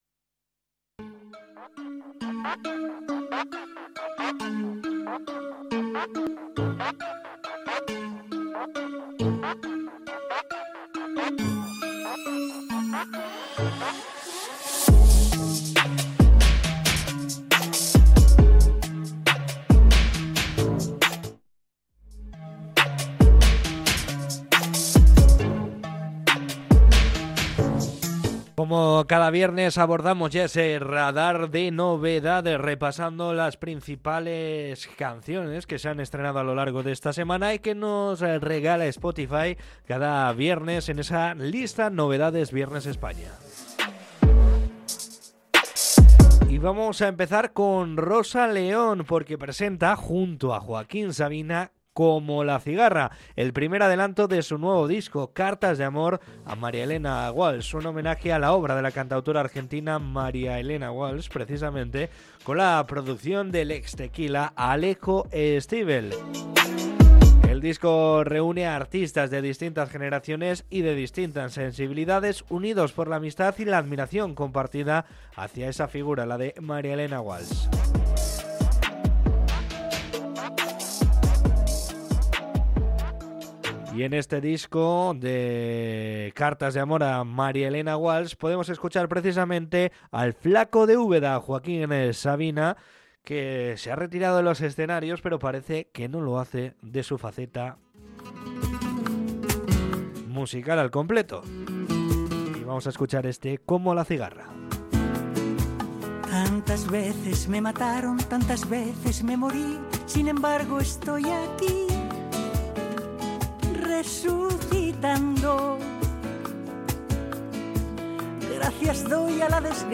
Podcast Música
Desde los estudios de Bilbao, el repaso ha estado marcado por un homenaje intergeneracional y una fuerte presencia de ritmos urbanos y electrónicos.